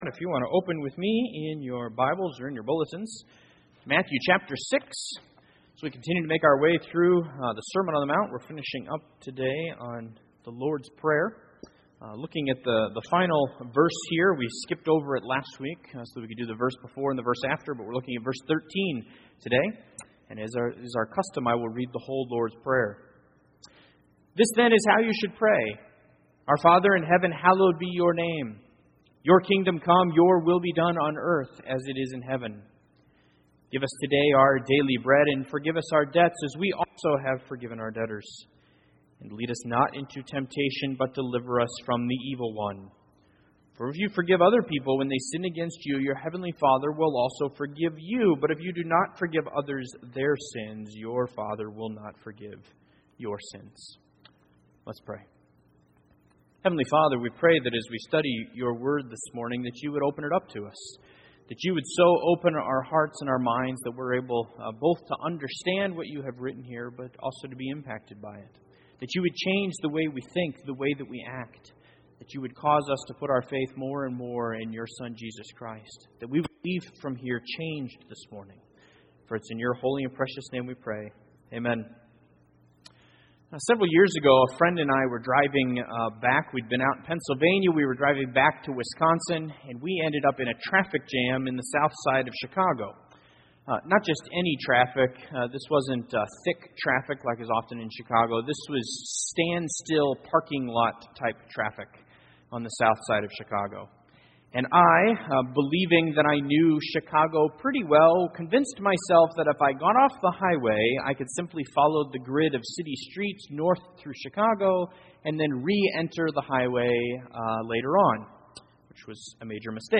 Matthew 6:13 Service Type: Sunday Morning This prayer is one for direction